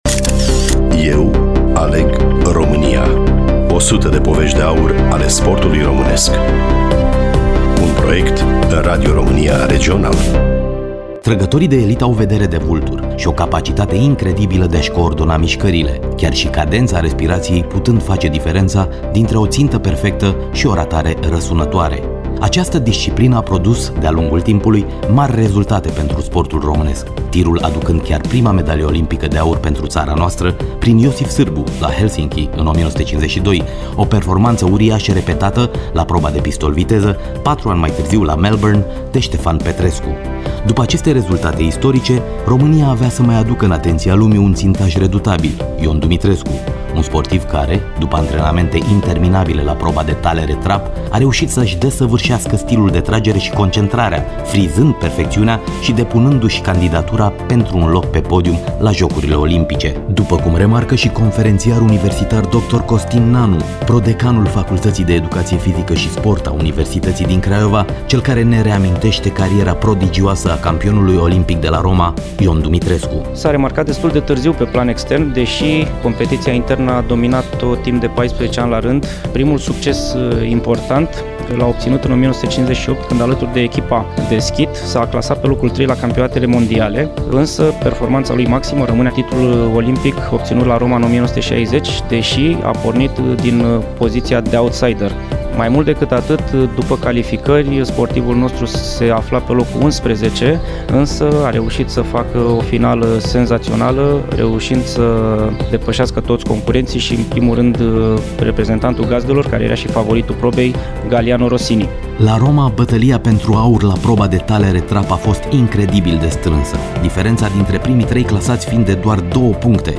Studioul Regional Radio România Cluj